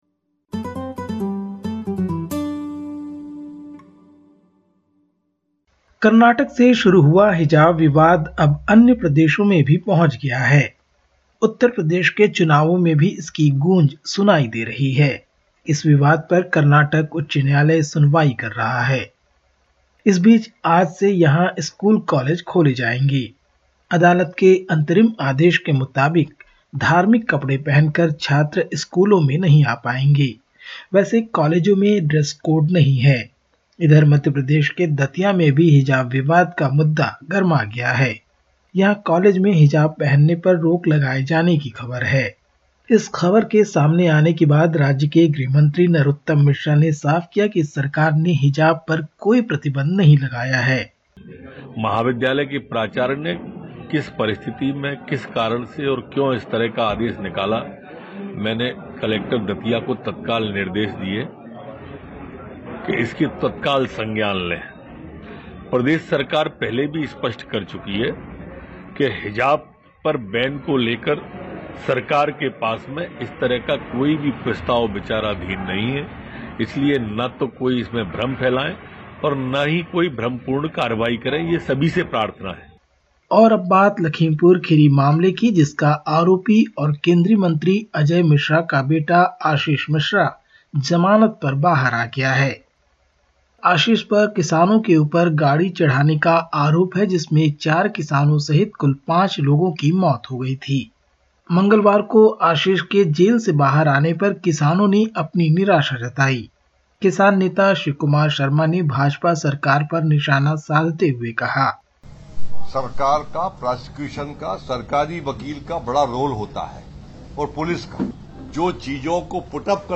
Listen to the latest SBS Hindi report from India. 16/02/2022